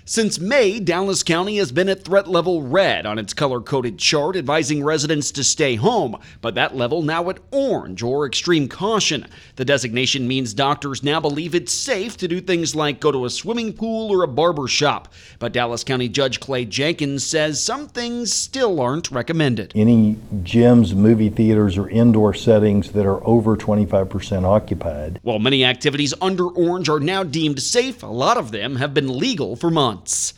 Dallas County Judge Clay Jenkins says some activities still aren’t recommended.